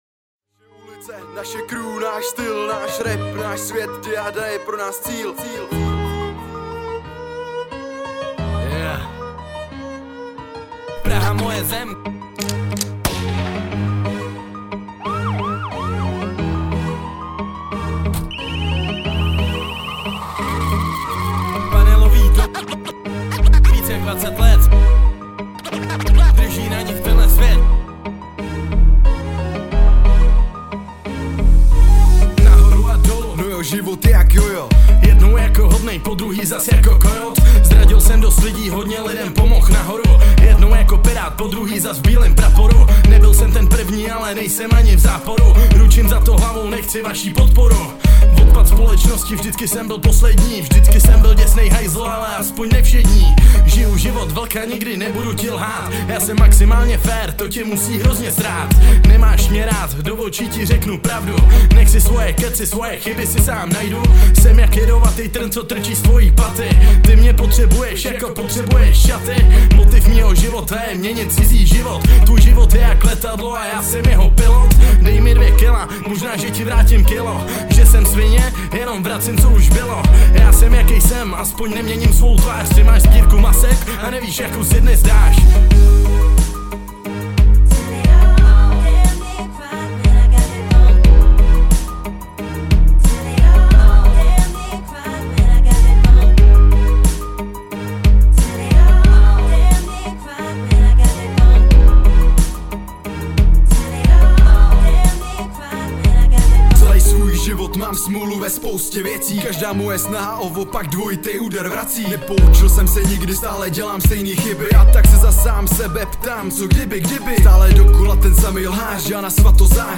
Styl: Hip-Hop